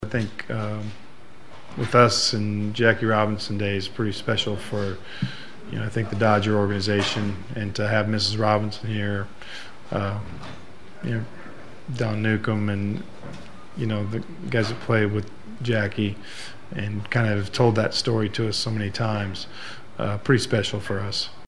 I also got comments from a few of the guys about wearing the 42’s and how special it is to be a Dodger on Jackie Robinson Day…first manager Don Mattingly…